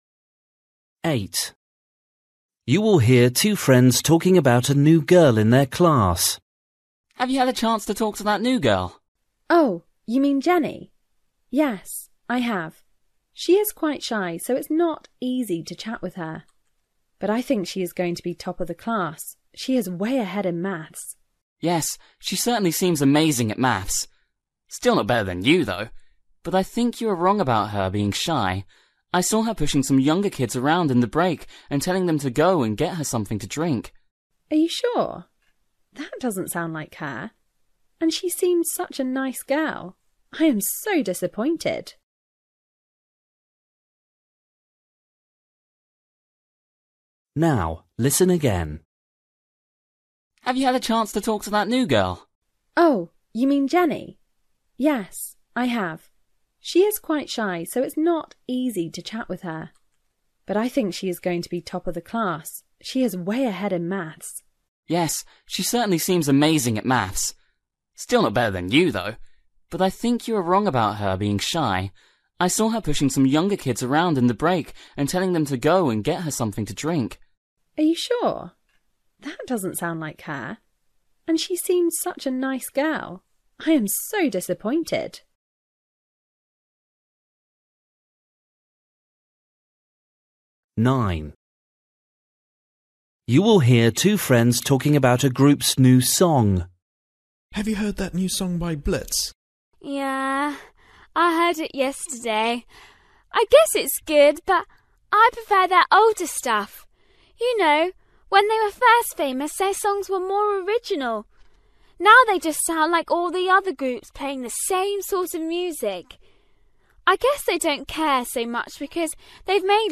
Listening: everyday short conversations
8   You will hear two friends talking about a new girl in their class. What is the boy’s opinion of her?
10   You will hear a girl telling a friend about something she bought online. What does the boy say about his experience?